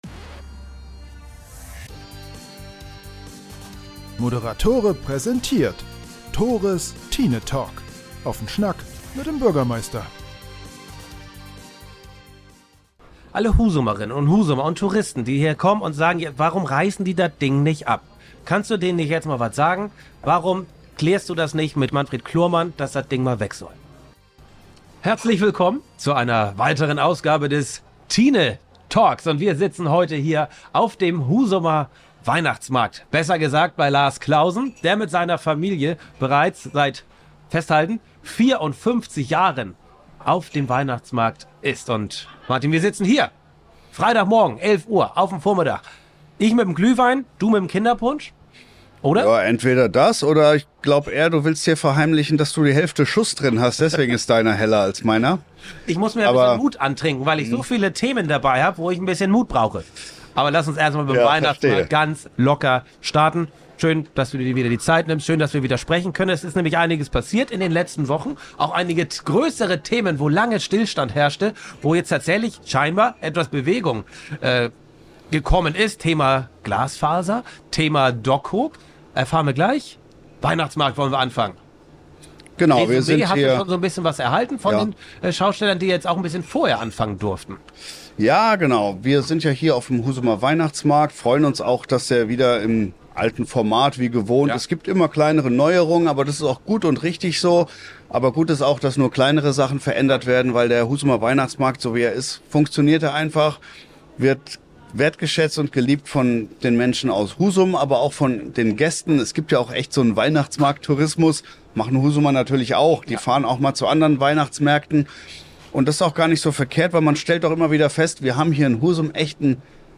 ndlich Bewegung in Sachen Glasfaser; Update zur Situation am Dockkoog und Live-Talk vom Husumer Weihnachtsmarkt.